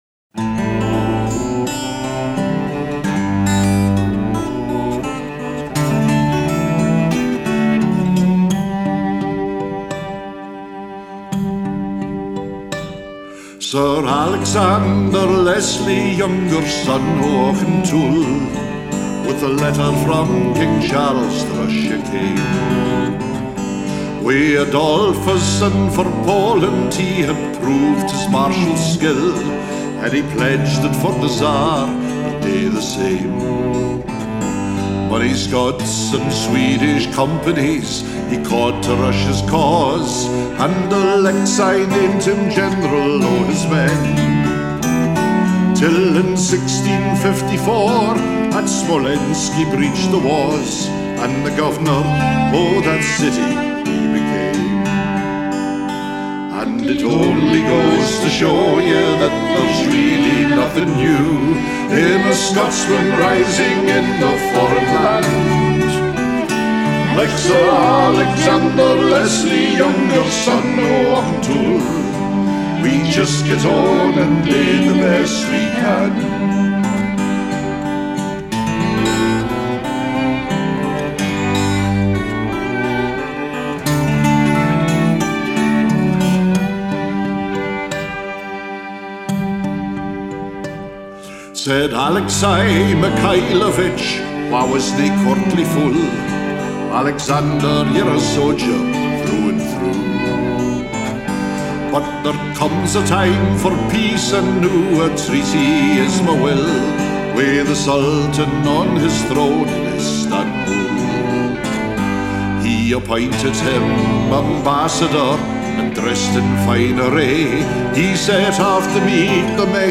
There is an exception in Line 7 of V1, where there are only 6 stresses – that’s because there is a pause in the vocal, but the regular beat is maintained by the instrumental backing.
Rhythmically, the song is mainly in dah-DUMs (iambs), with some dah-dah-DUMs (anapests) and DUM-dahs (trochees).
It doesn’t make any difference to the length of the line, nor to where the stresses occur – so the listener would perceive the rhythm as constant.